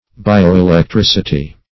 bioelectricity - definition of bioelectricity - synonyms, pronunciation, spelling from Free Dictionary
bioelectricity.mp3